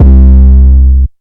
808 - No Heart [ C ].wav